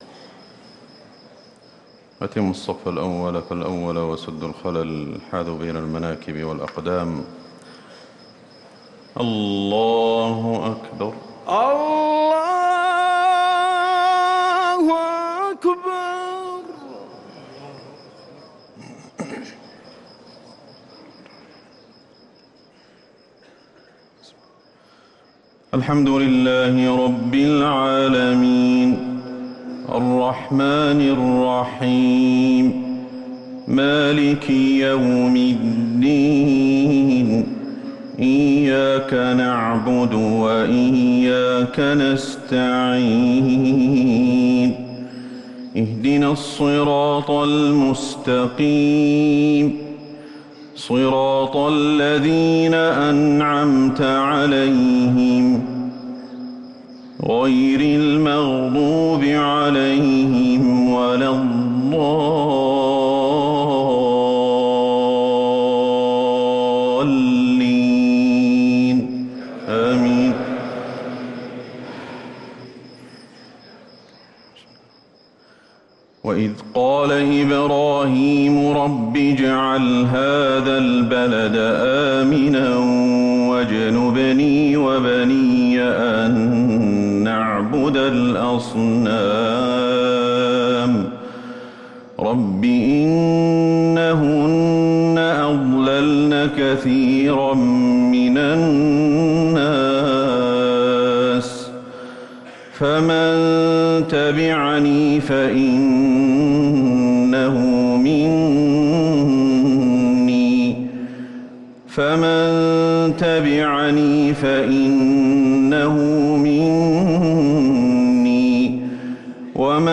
صلاة الفجر للقارئ أحمد الحذيفي 7 شوال 1444 هـ
تِلَاوَات الْحَرَمَيْن .